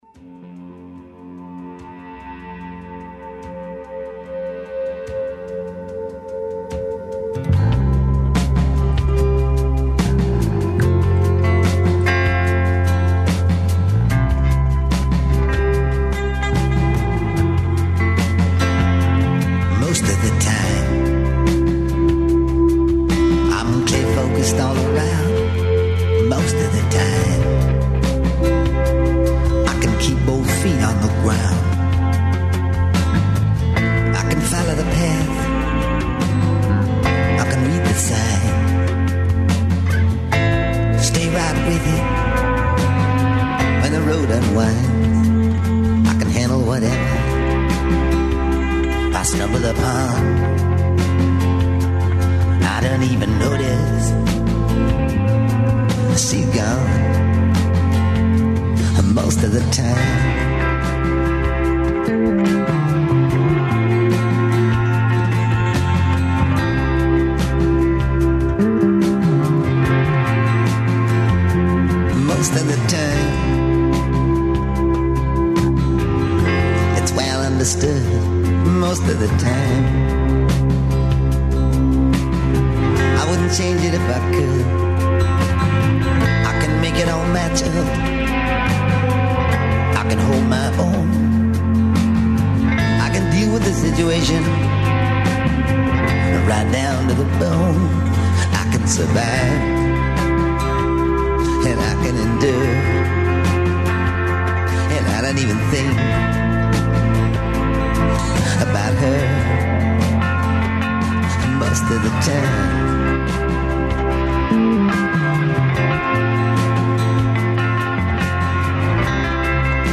Ж иво, у нашем студију, свираће Дубиоза Колектив.